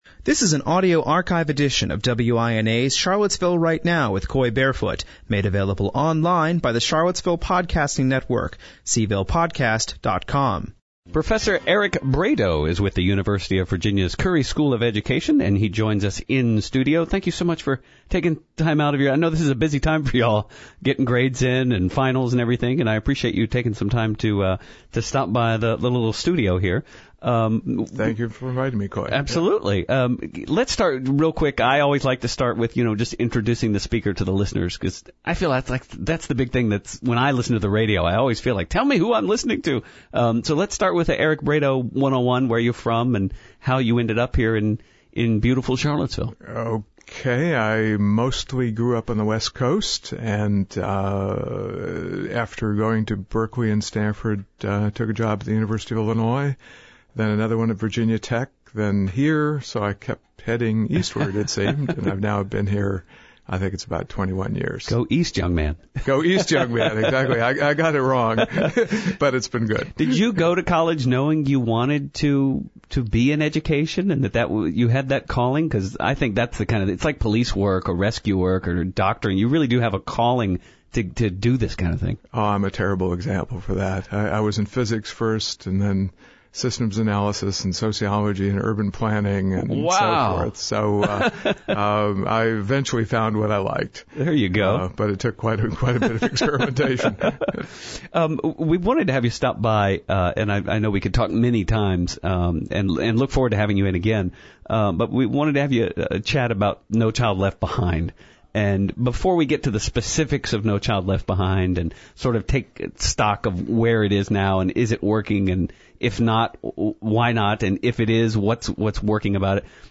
CharlottesvilleGuv,!vDjnjRight Now is broadcast live Monday through Friday on NewsRadio 1070 WINA from 4 to 6pm.
The Act has been criticized for relying more on standardized testing than actual education, but a special education teacher called in to offer his support of the Act.